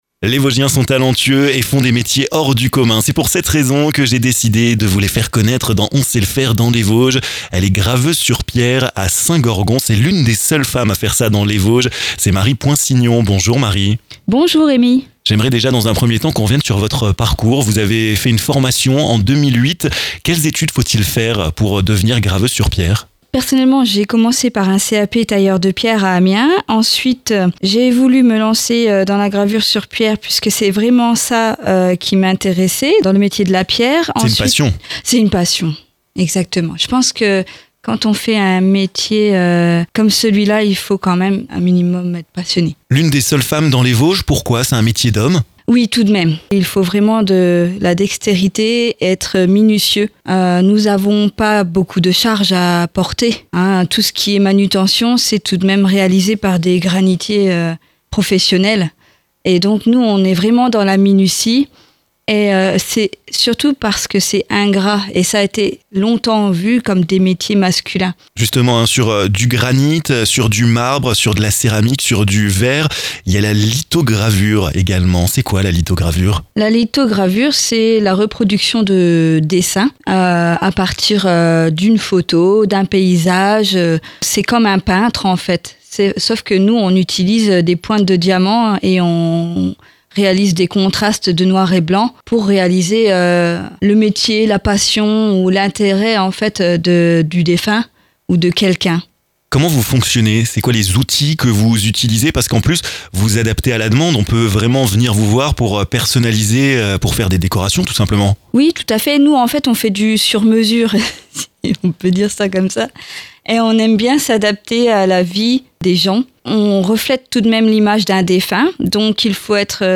Elle vous présente son savoir-faire dans cette nouvelle interview!